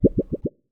pgs/Assets/Audio/Comedy_Cartoon/bubble_effect_02.wav at master
bubble_effect_02.wav